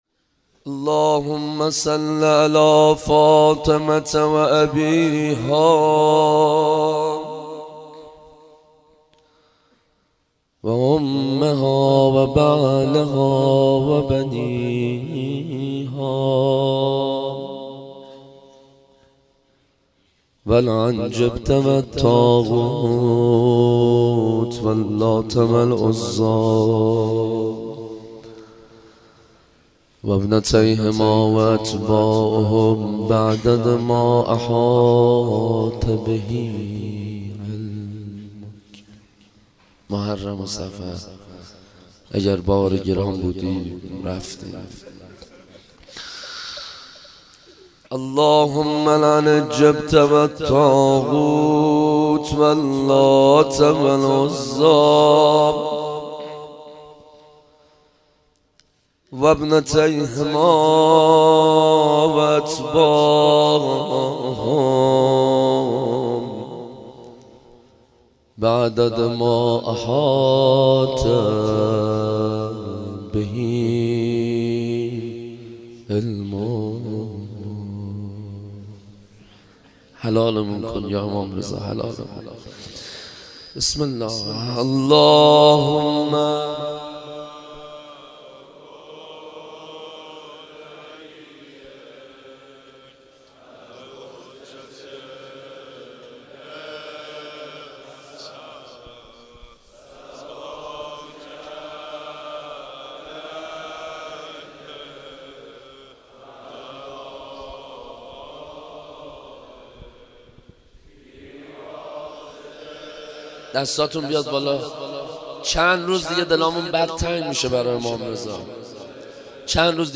روضه امام رضا